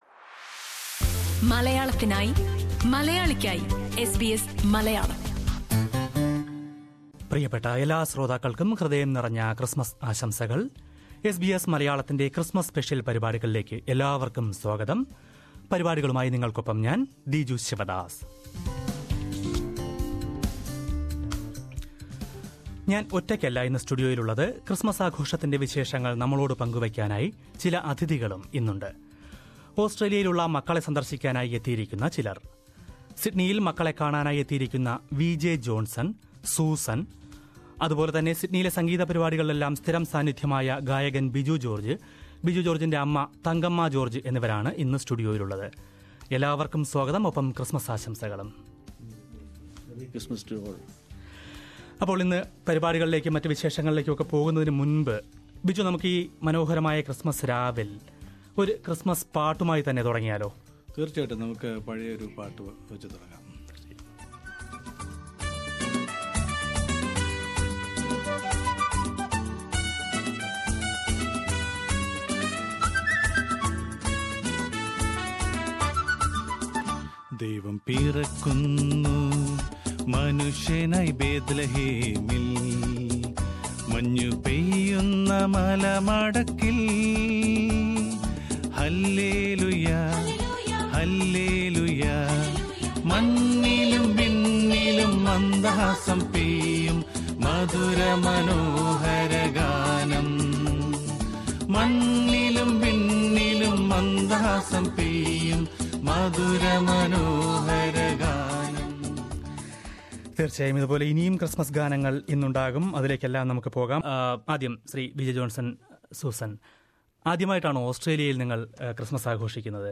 On the Christmas eve, a group of parents who visit their kids in Australia joined SBS Malayalam to share their Christmas stories.